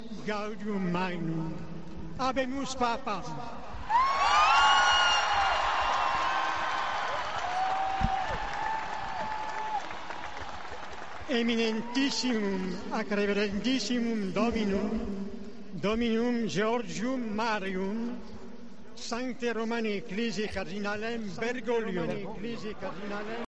“Absolutamente enloquecida la gente en cuanto ha visto esa fumata blanca […] suenan todas las campanas ahora mismo. La gente está dando gritos de alegría y efectivamente se confirma por las pantallas”, dijeron los dos periodistas directamente desde la Plaza.